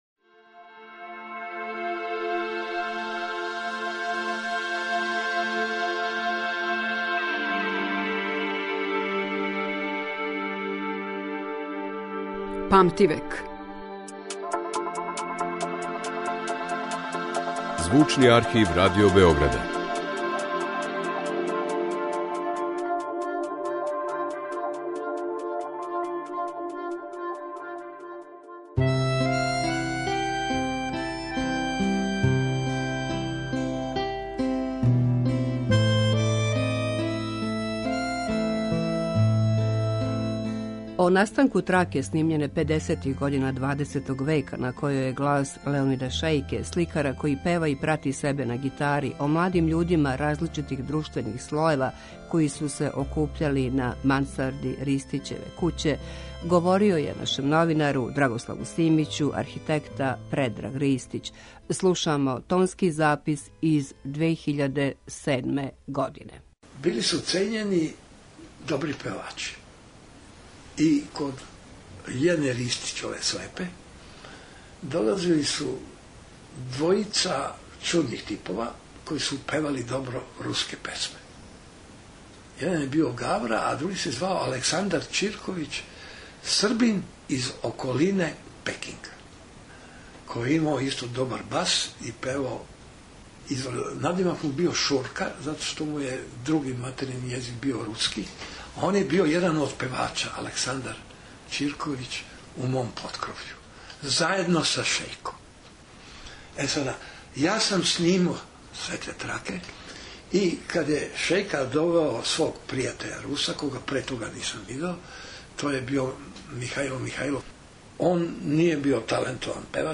Тонски запис из 2007. године
Слушамо тонски запис из 2007. године.